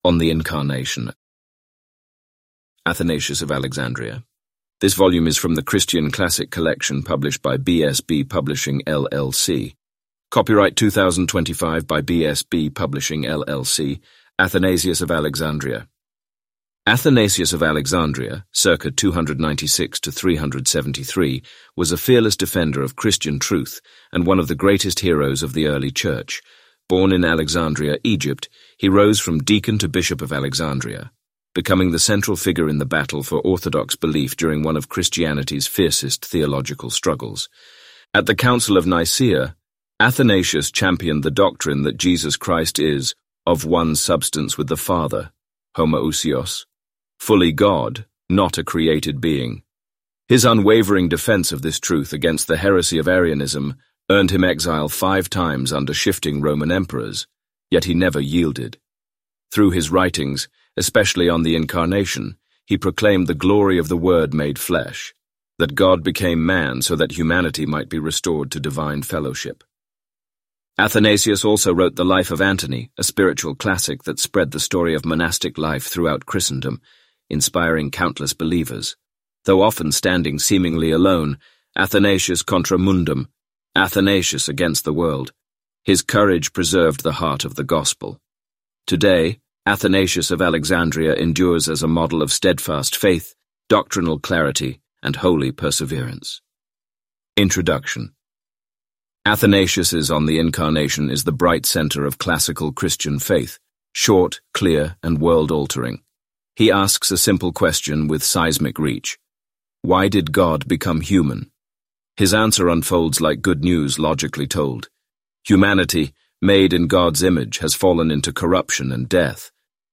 Complete Audiobook Play Download Individual Sections Listening Tips Download the MP3 files and play them using the default audio player on your phone or computer.